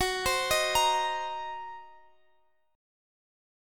Listen to F#6b5 strummed